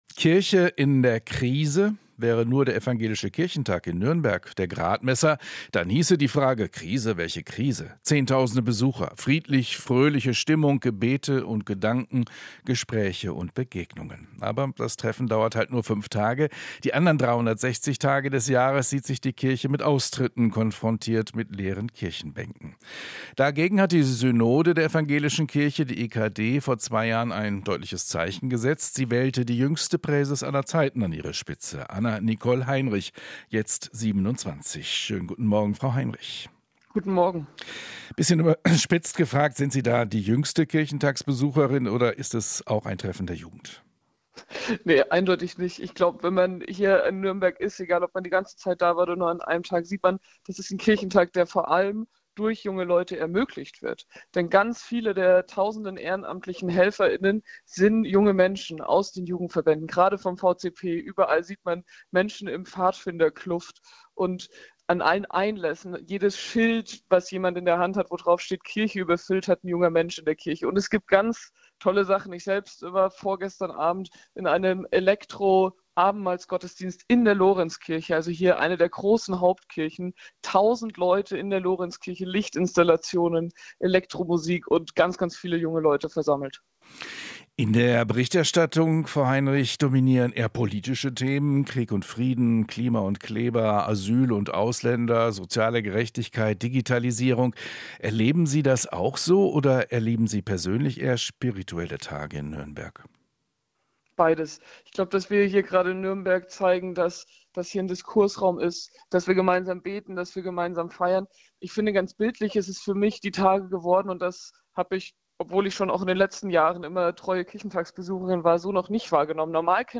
Interview - Evangelischer Kirchentag: "Politisch sein gehört zum Christ sein"
Dort geht es traditionell nicht nur um religiöse Themen, sondern auch um politische Debatten. Der Kirchentag sei ein Diskursraum, demonstriere aber auch gesellschaftlichen Zusammenhalt, sagt Anna-Nicole Heinrich, Präses der Synode der Evangelischen Kirche in Deutschland.